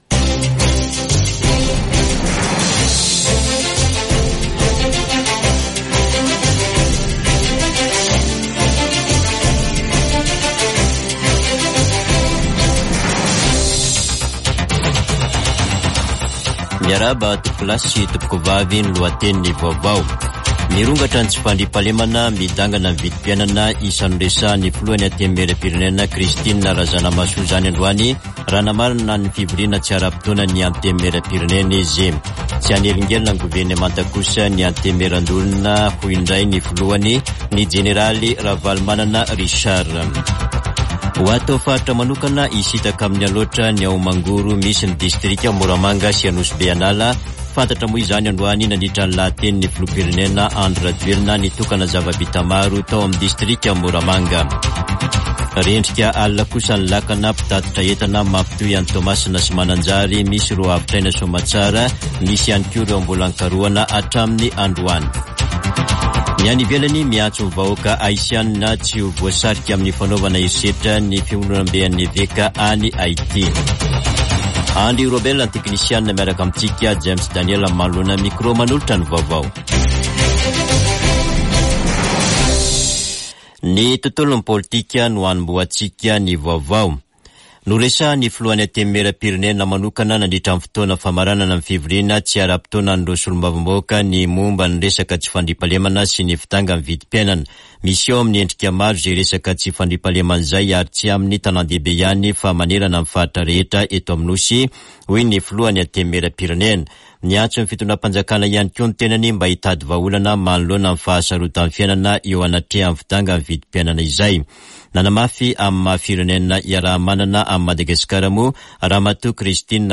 Radio Don Bosco - [Vaovao hariva] Zoma 9 febroary 2024